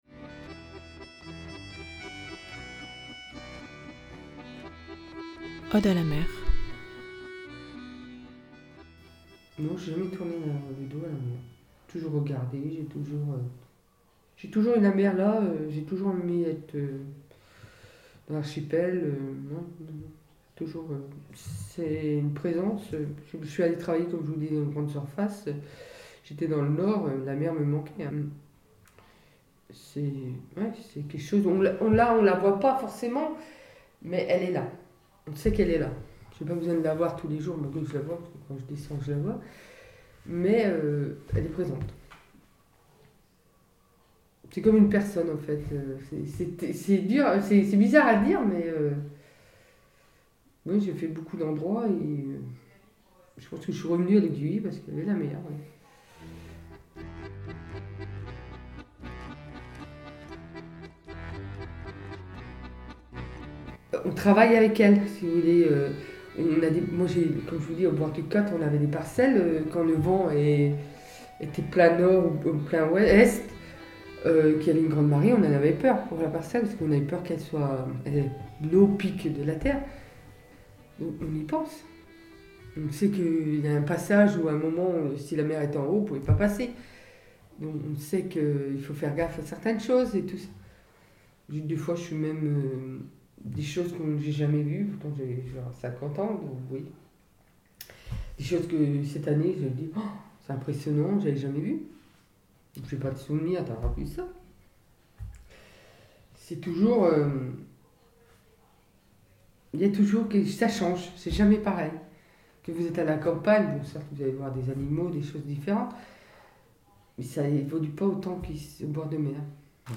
Exposition sonore et photo itinérante